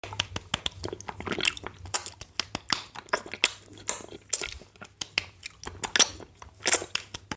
ASMR Lollipop Eating & Slapping sound effects free download
ASMR Lollipop Eating & Slapping Sounds